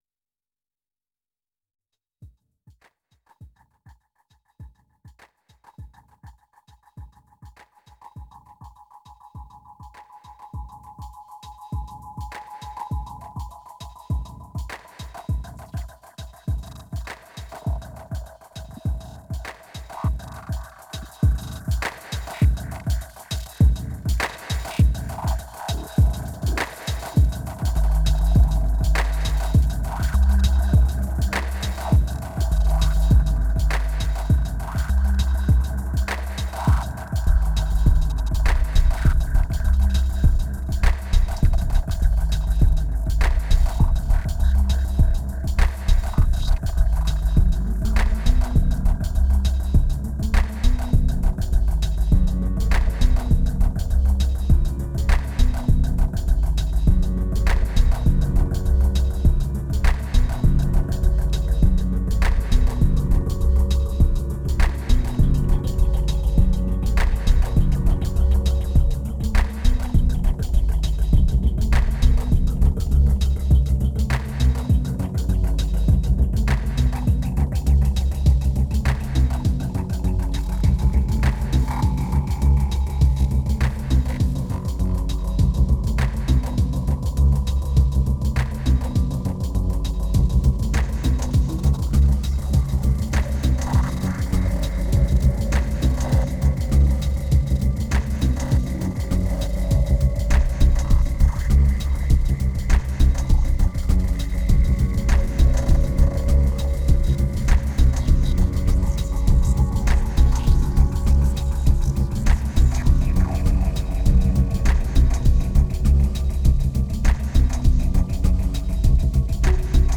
winter moods recorded in Paris
1977📈 - -51%🤔 - 101BPM🔊 - 2010-12-04📅 - -252🌟